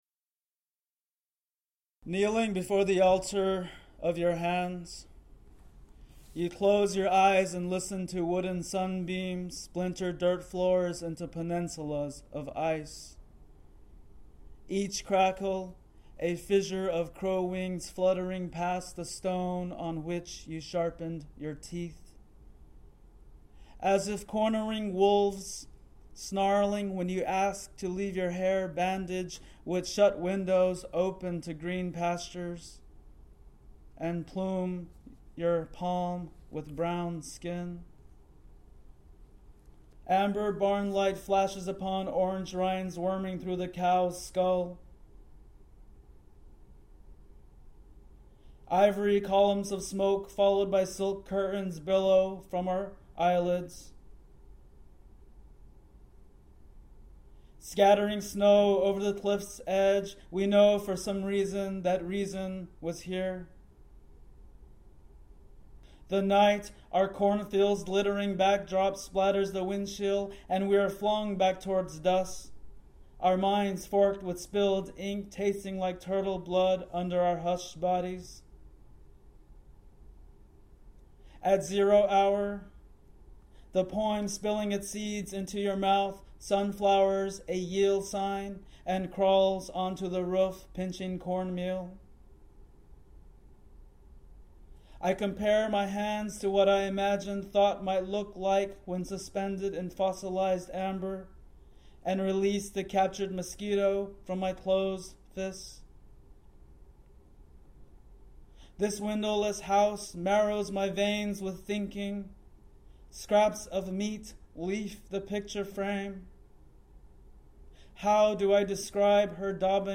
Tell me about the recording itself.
Untitled (live, Colby) | Fishouse Untitled-liveColby.mp3